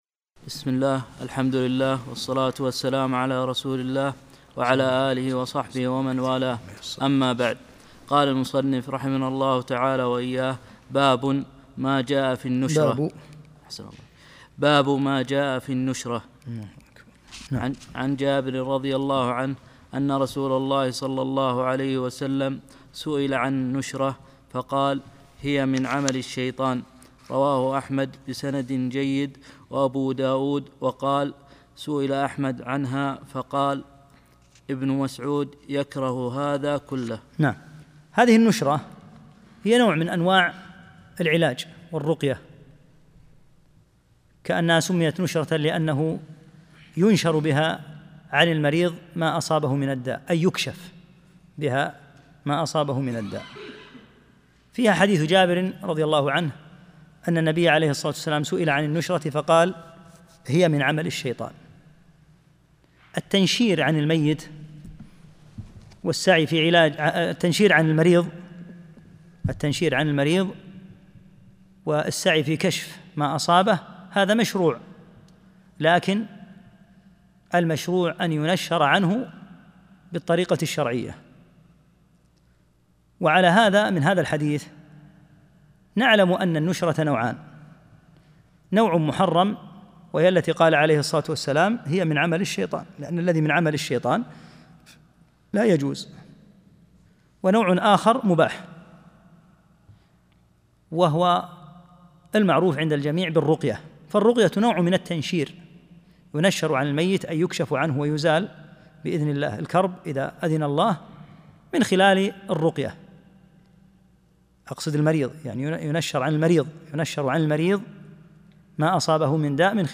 26- الدرس السادس والعشرون